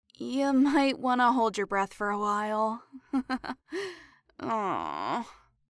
farty4.mp3